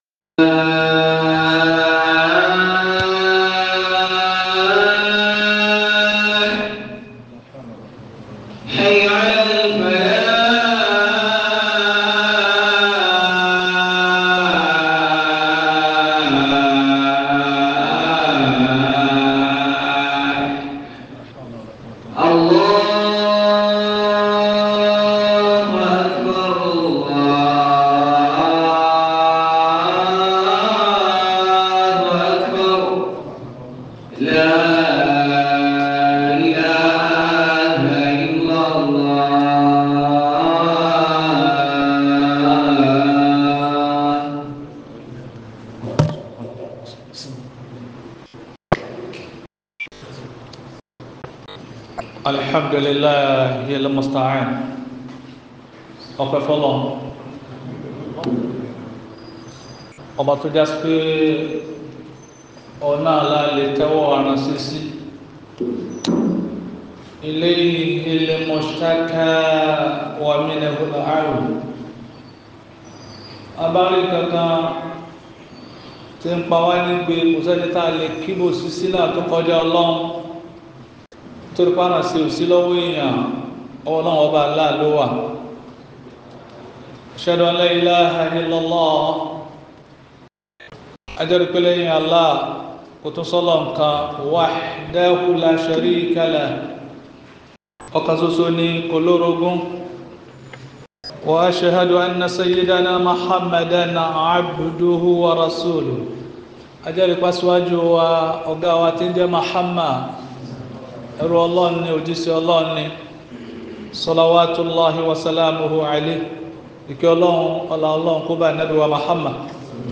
This khutbah discusses the unlimited mercy of Allah and how believers can connect more deeply with Him during hardship.